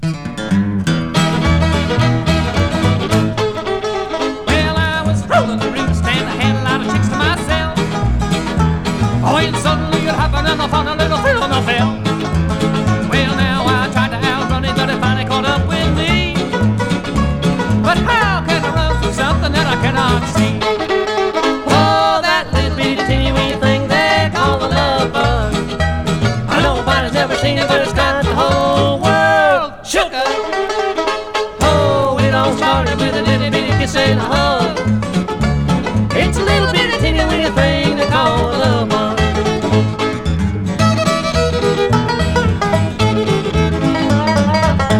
Country, Bluegrass　USA　12inchレコード　33rpm　Mono